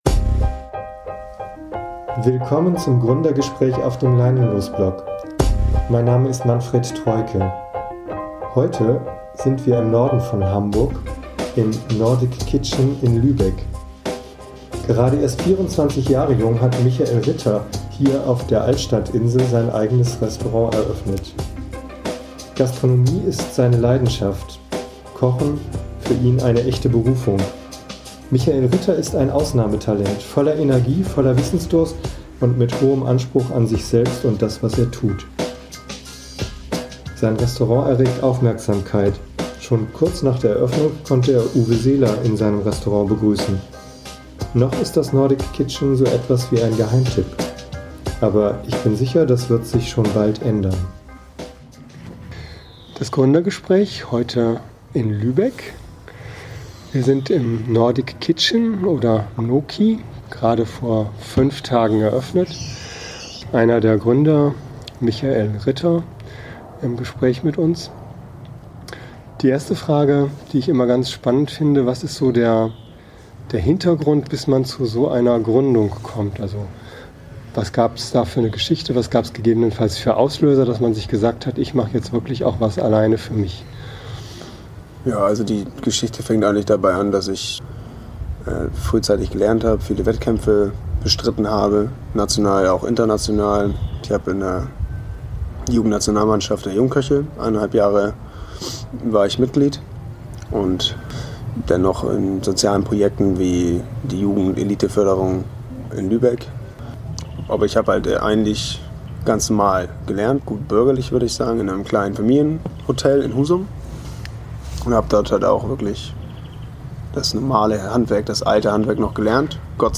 Interview-Download